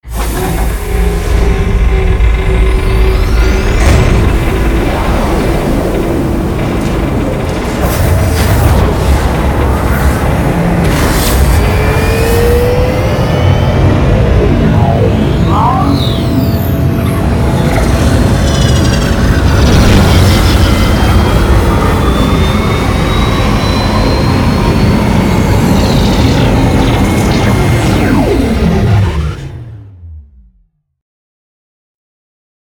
stargate.ogg